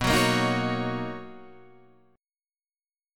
BM9 Chord
Listen to BM9 strummed